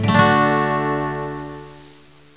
virtual guitar